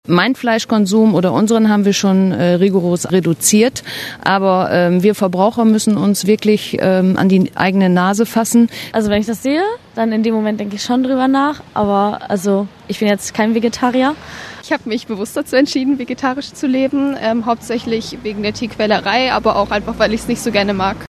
Auf der Straße haben wir die Menschen gefragt, wie sie zu Fleisch aus Massentierhaltung stehen.
Umfrage